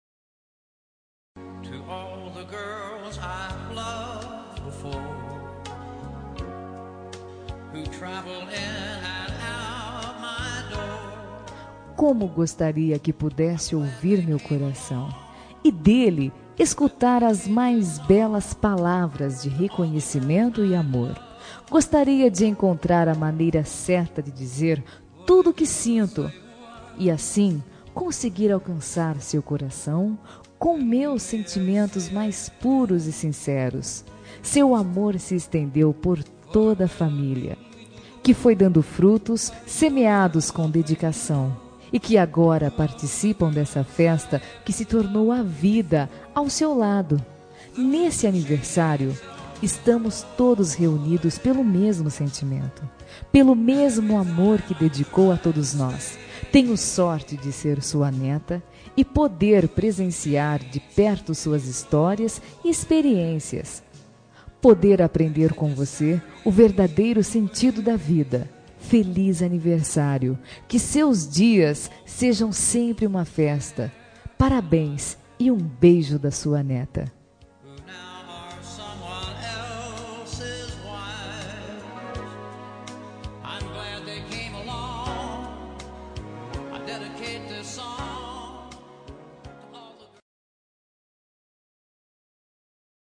Aniversário de Avô – Voz Feminina – Cód: 2092